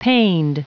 Prononciation audio / Fichier audio de PANED en anglais
Prononciation du mot paned en anglais (fichier audio)